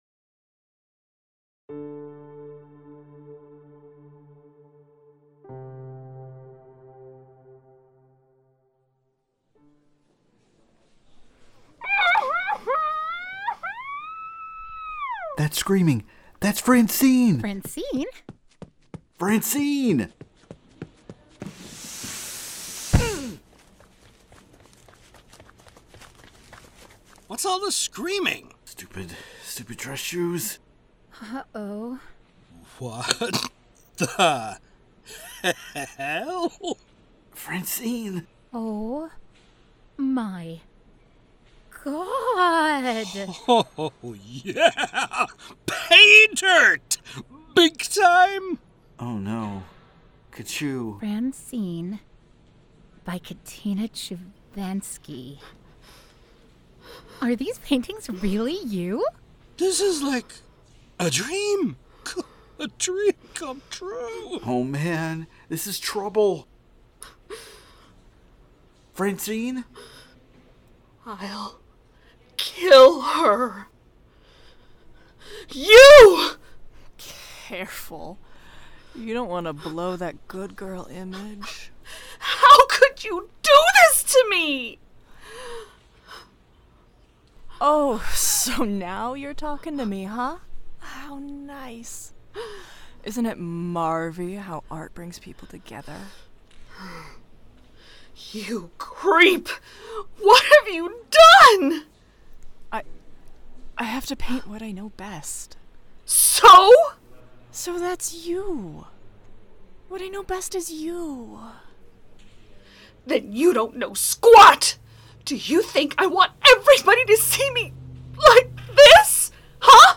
Strangers In Paradise – The Audio Drama – Book 7 – Episode 9 – Two True Freaks